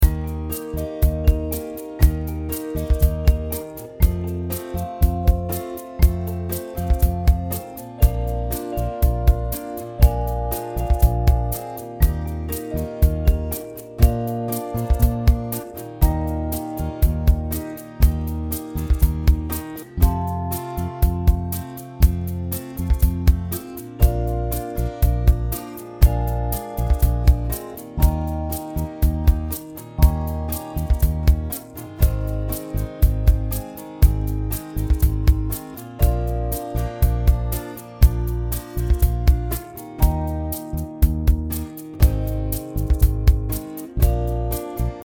A sweet Thanksgiving song